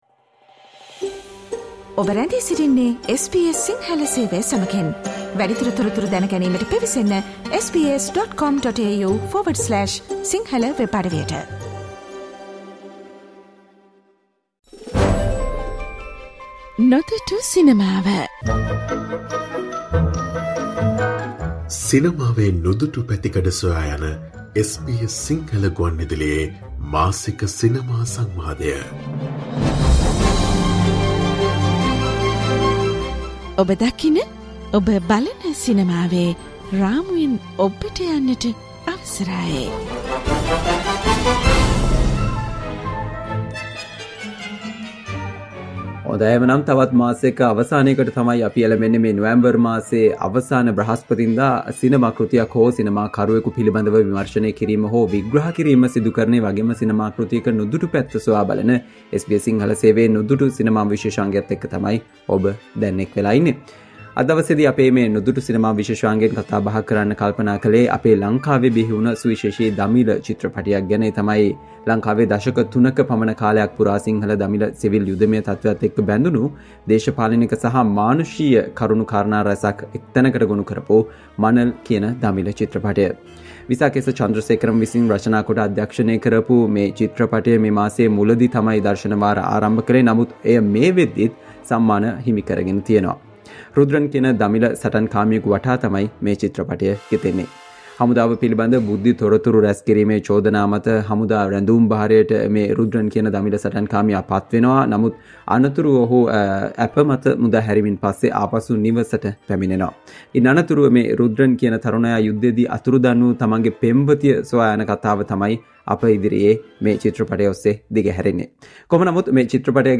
SBS සිංහල ගුවන් විදුලියේ මාසික සිනමා විශේෂාංගය වන "නොදුටු සිනමාවෙන්" මෙවර " මනල් " දමිළ චිත්‍රපටය පිළිබඳ කතා බහක්.